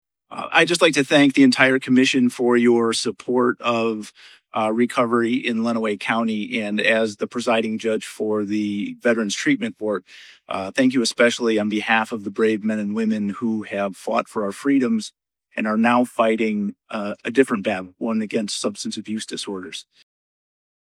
During the presentation, Judge Michael Olsaver, who presides over the Veterans Treatment Court, spoke about the ongoing fight against substance abuse disorders.
That was Judge Olsaver.